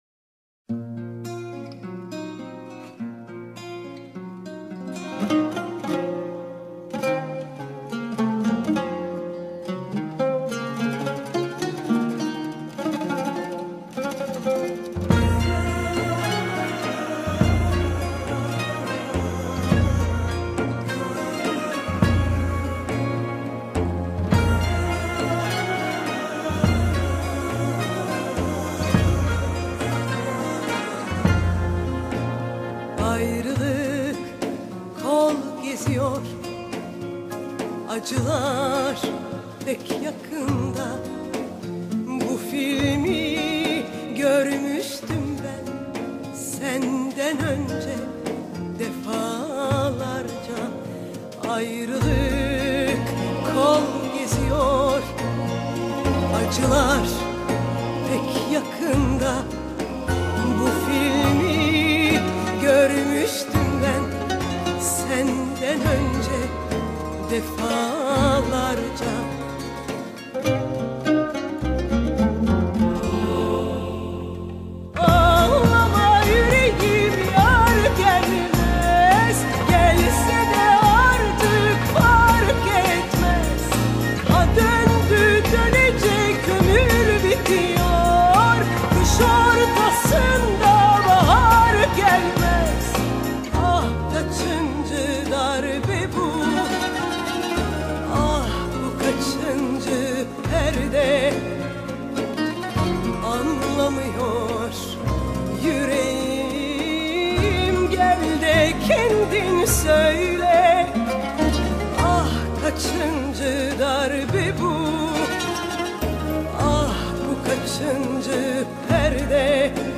مجموعه‌ای نوستالژیک از آهنگ‌های خاطره‌انگیز موسیقی پاپ ترکی
Turkish Pop, Pop, Anatolian Rock, Arabesque